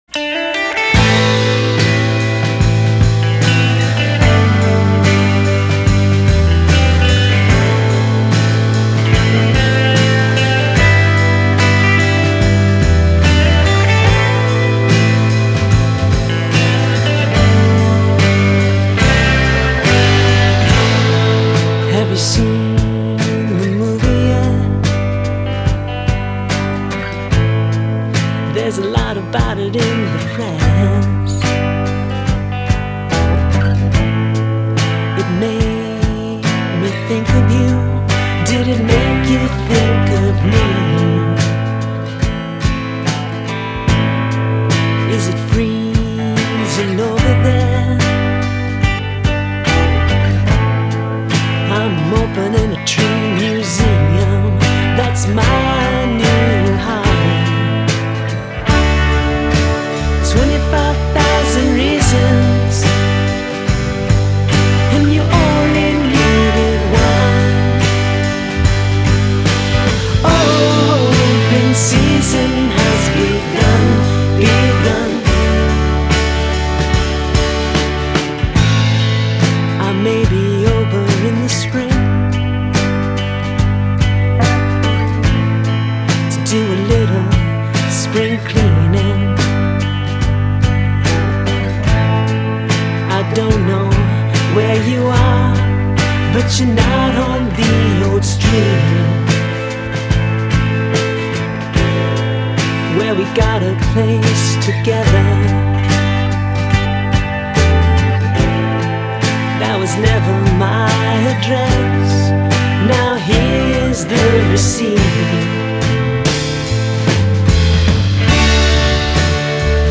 Genre: Indie, Alternative